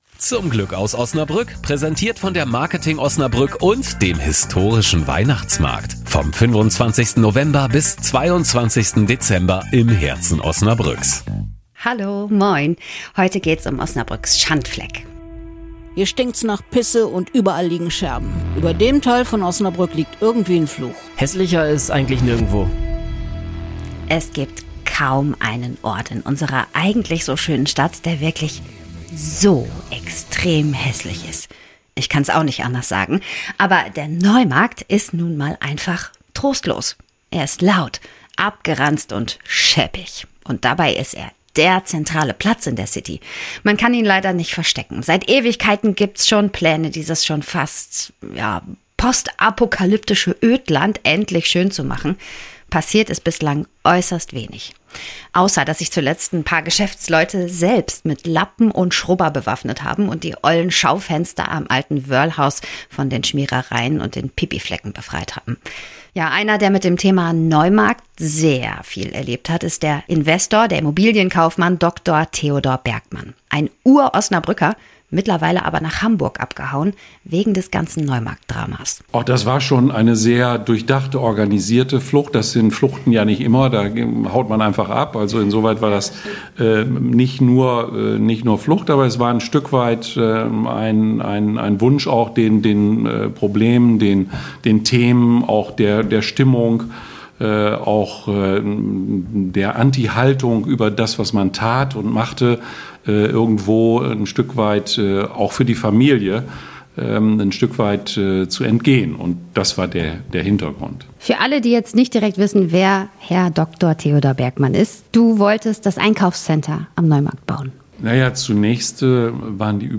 Mit ihr bin ich über die Johannisstraße geschlendert, vorbei an Pipigeruch und Scherbenhaufen in Schaufenstern.